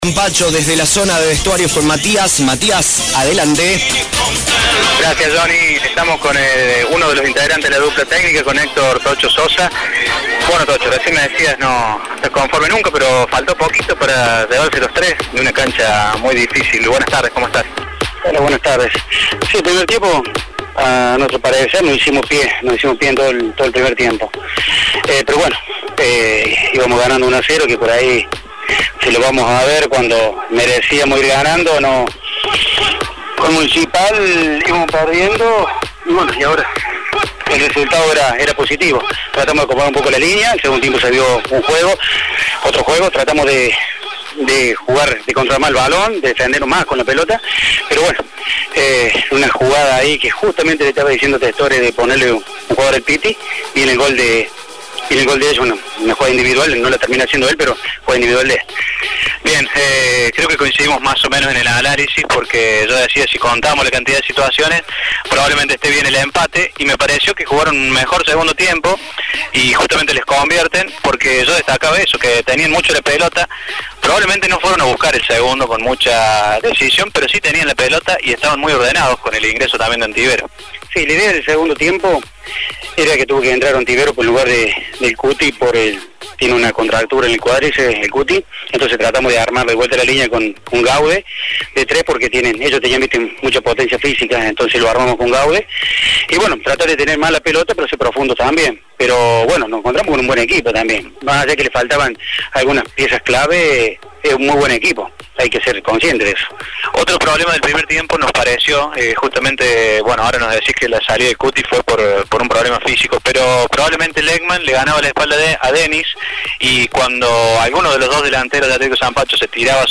luego de finalizado el encuentro en cancha de Atlético Sampacho